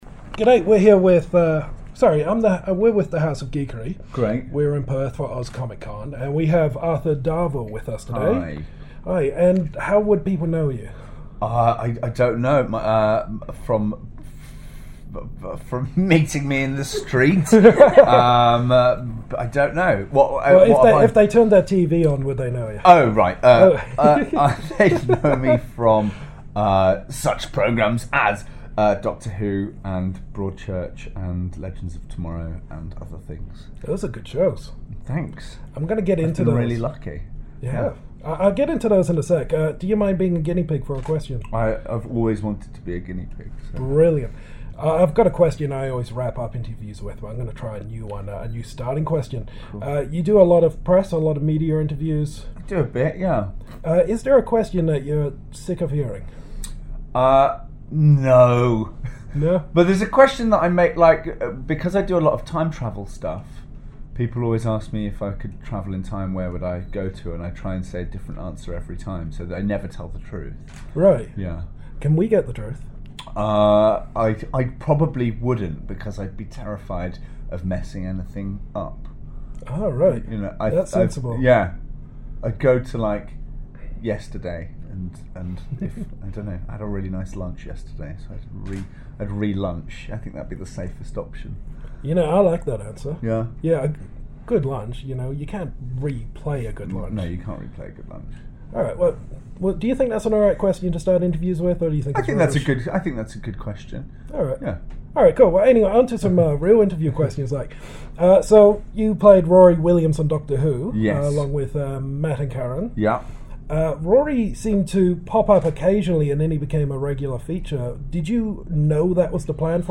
Exclusive Interview with Arthur Darvill!
When he isn’t composing music, performing on stage or practising magic tricks he’s travelling through time in some of the best science-fiction time travelling adventures seen on TV. When he came to town for Oz Comic-Con we took the chance to catch-up with the man out of time!